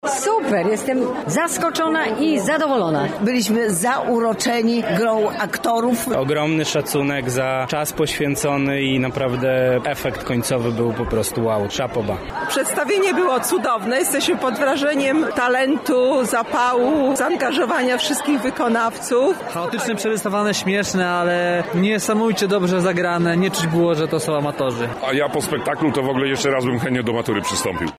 O wrażenia po spektaklu zapytaliśmy widzów:
widzowie_01.mp3